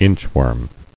[inch·worm]